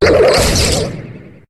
Cri de Noacier dans Pokémon HOME.